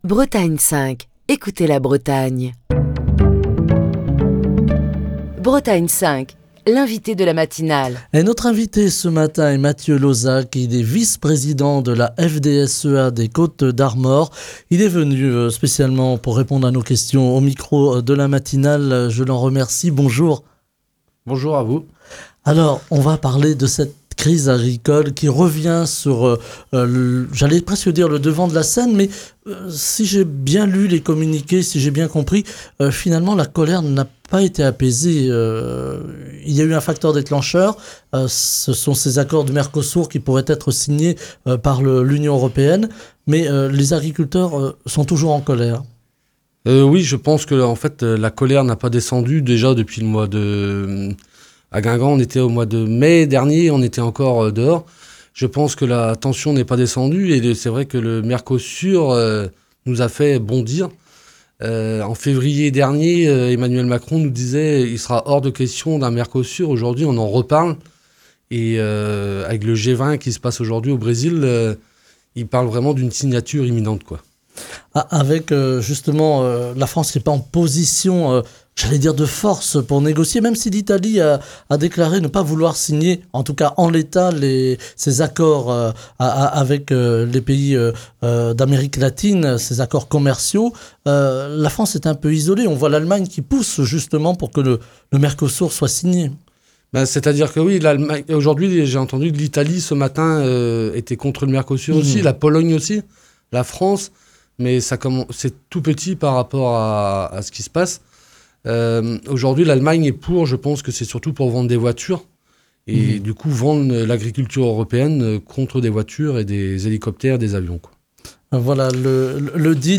Émission du 19 novembre 2024. La mobilisation nationale des agriculteurs prend de l'ampleur tout le territoire.